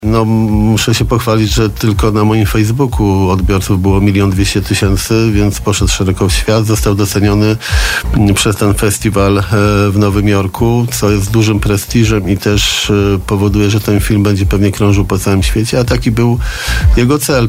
Dziś w Radiu Bielsko prezydent Jarosław Klimaszewski chwalił się, że tylko na jego Facebooku film „Bielsko-Biała – miasto zawsze dla ludzi” zobaczyło już 1 mln 200 tys. odbiorców.